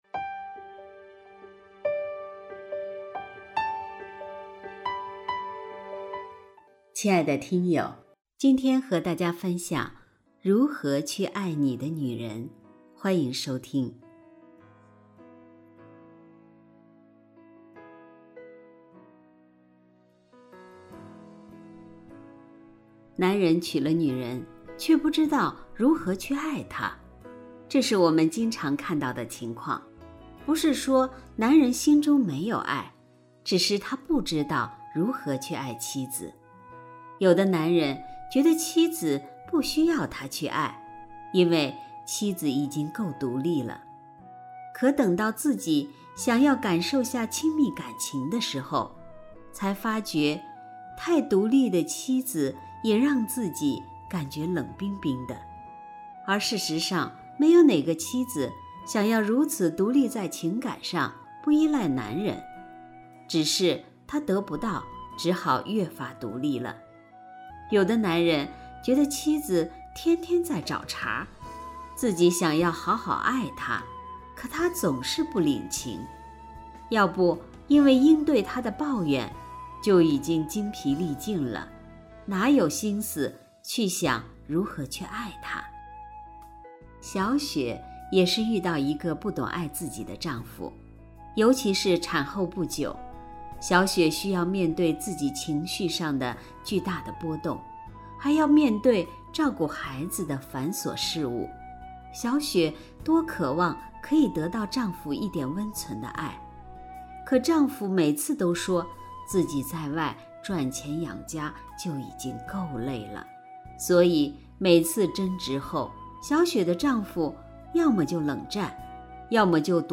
首页 > 有声书 > 婚姻家庭 > 单篇集锦 | 婚姻家庭 | 有声书 > 如何去爱你的女人